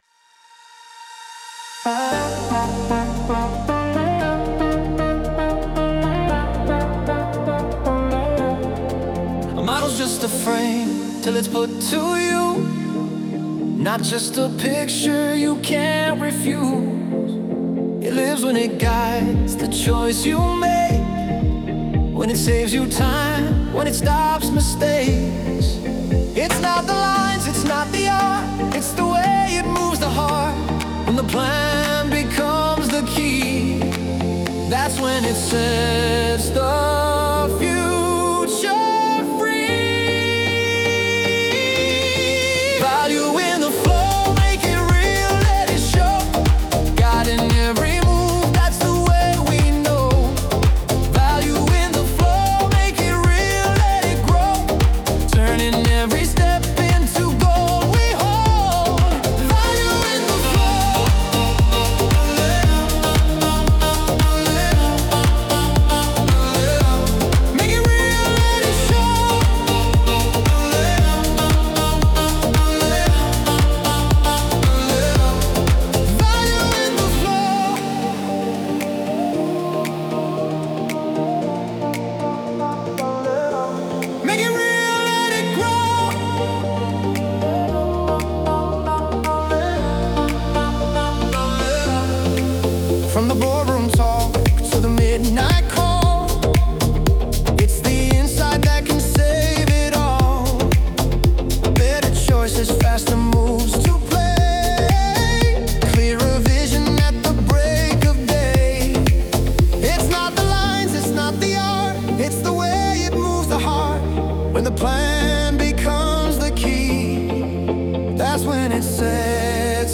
Tropical House · 115 BPM · Eng